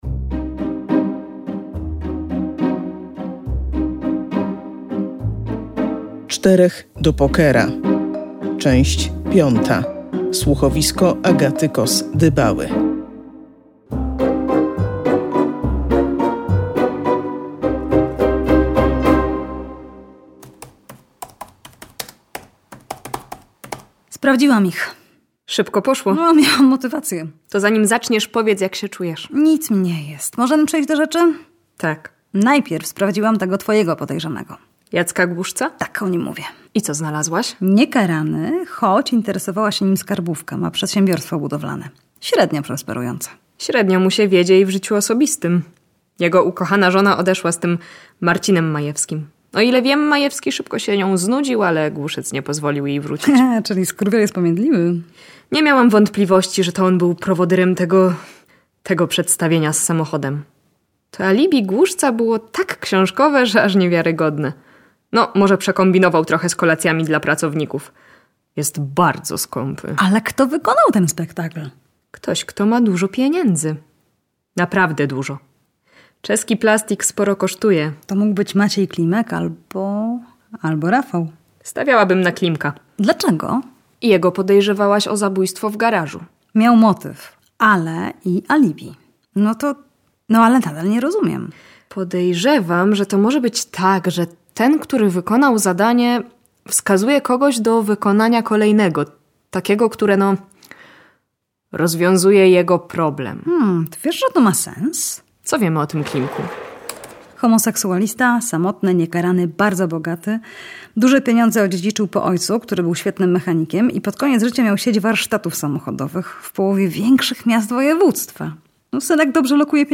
W ostatni lipcowy wieczór – piąta część słuchowiska „Czterech do pokera”.